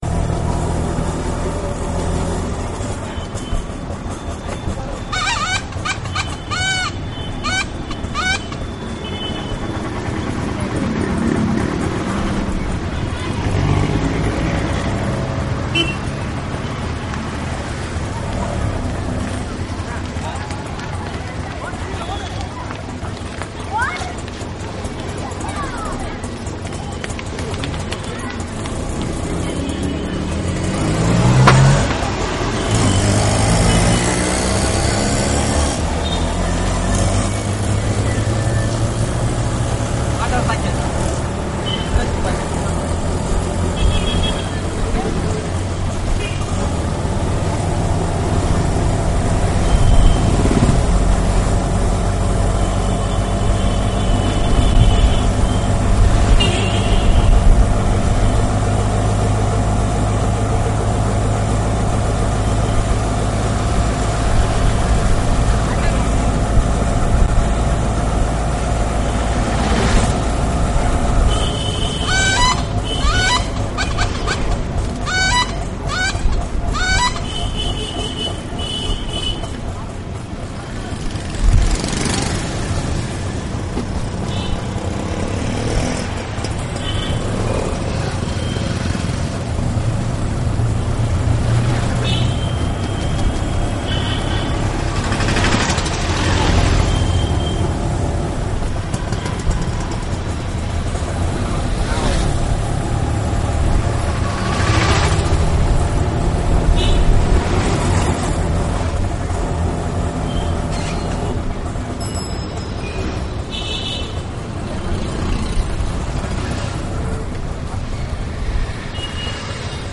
Descarga de Sonidos mp3 Gratis: trafico 3.
autorikshaw_1.mp3